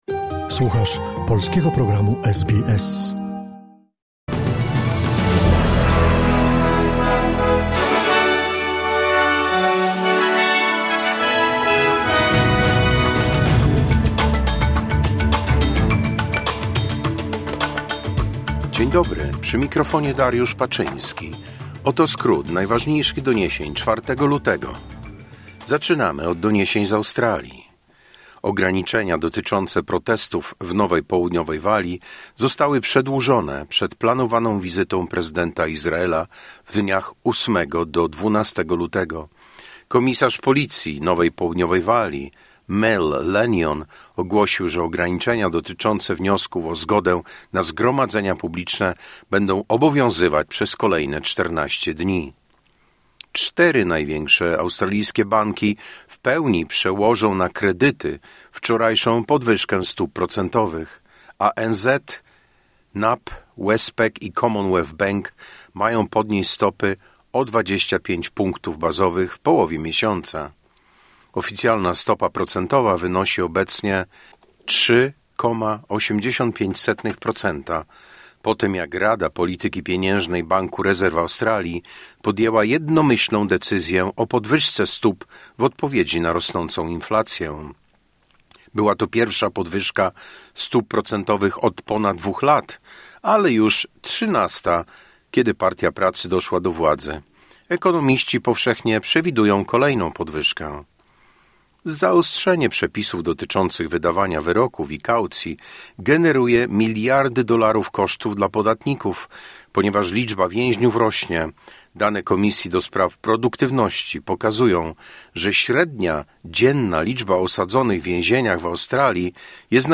Wiadomości 4 Lutego SBS News Flash